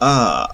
41 ɐ vowel near-open central unrounded [
near-open_central_unrounded_vowel.wav